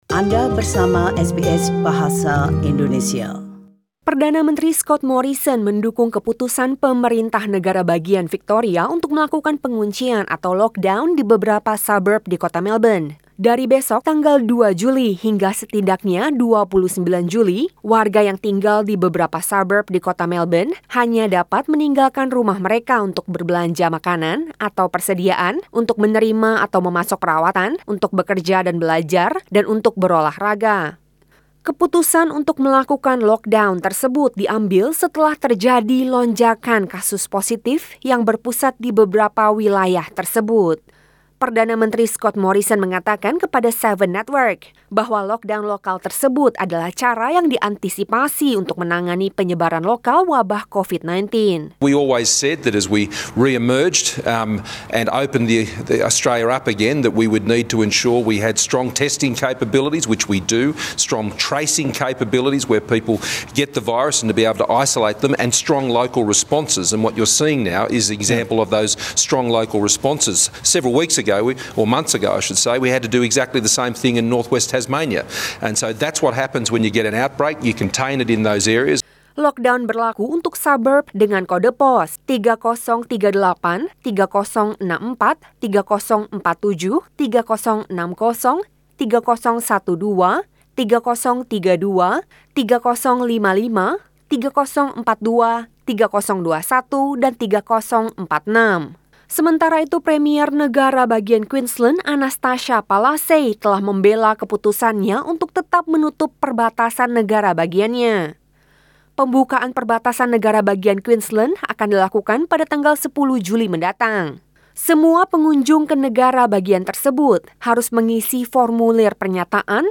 SBS Radio News in Bahasa Indonesia - 1 July 2020